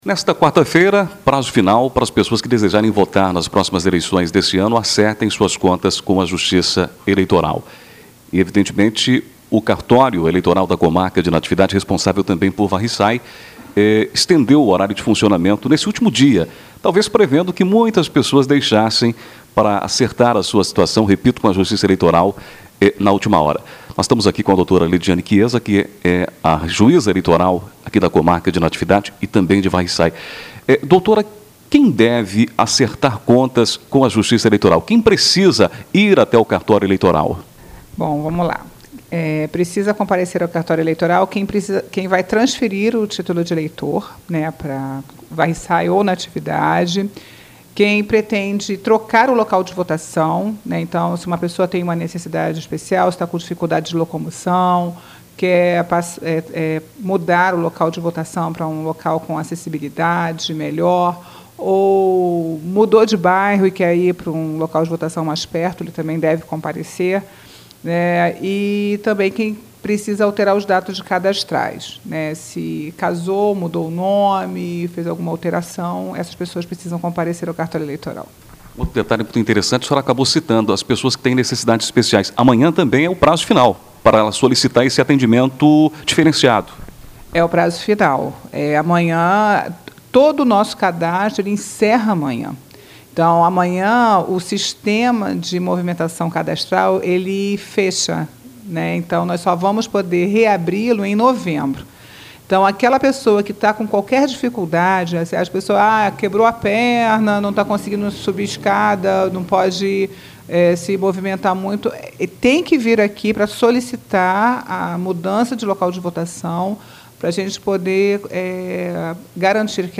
9 maio, 2018 ENTREVISTAS, NATIVIDADE AGORA